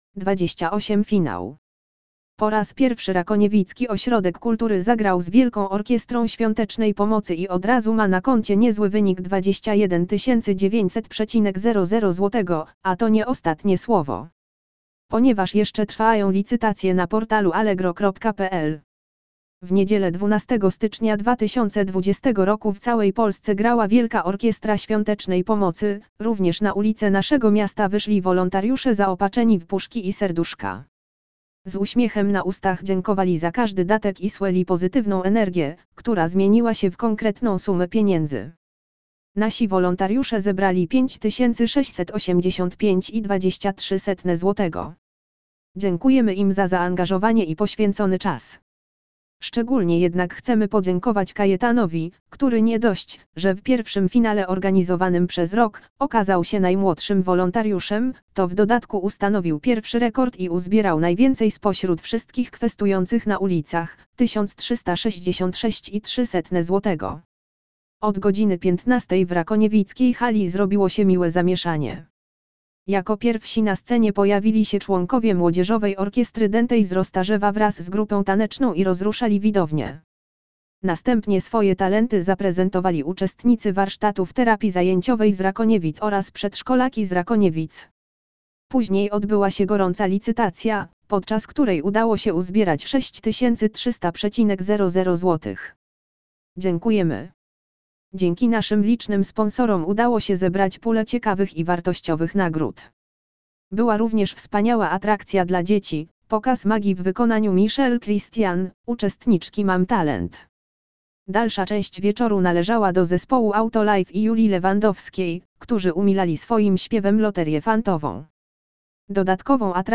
Lektor audio opis 28 FINAŁ WOŚP
lektor_audio_opis_28_final_wosp.mp3